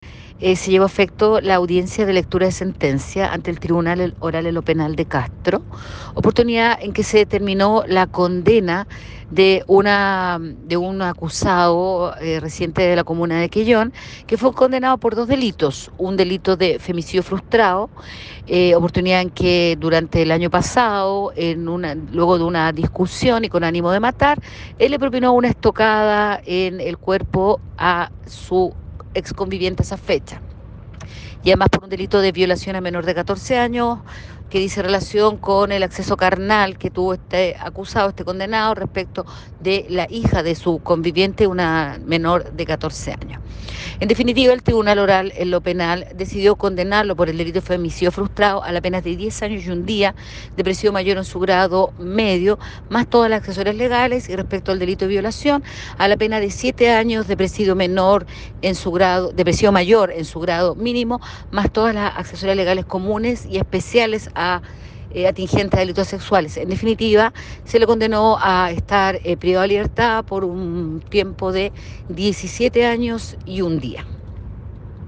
Quien detalló la determinación,  fue la fiscal del Ministerio Público, Karyn Alegría.